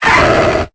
Cri de Lucario dans Pokémon Épée et Bouclier.